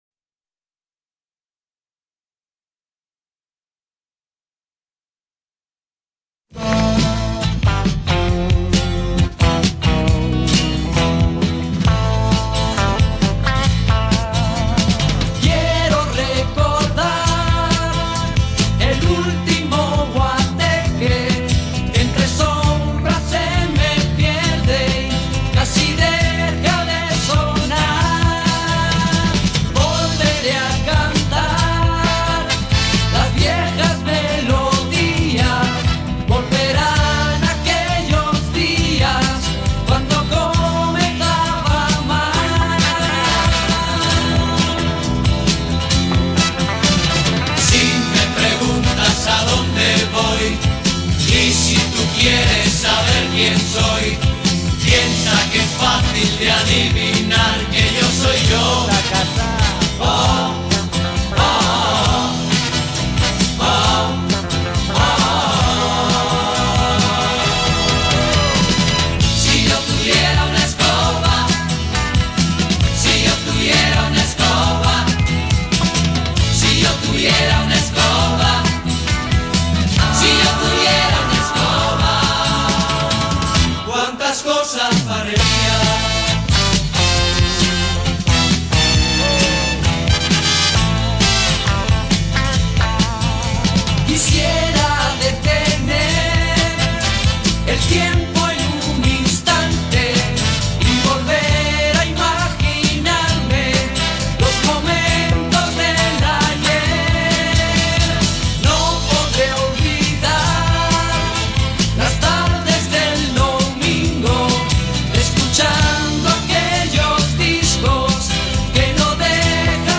lo cantaba un trío